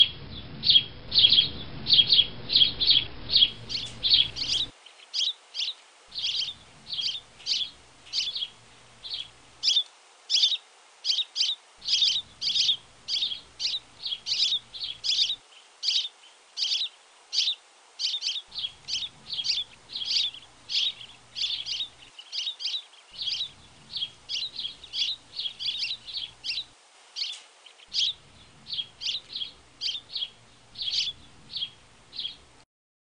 树麻雀群体叫声